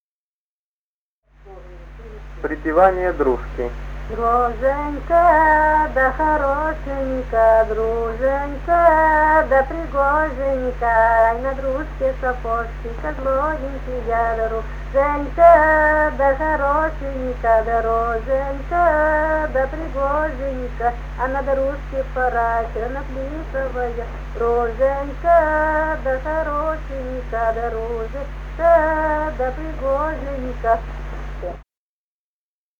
Русские народные песни Красноярского края.
« Друженька да хорошенький» (свадебная, величальная дружке). с. Яркино Богучанского района.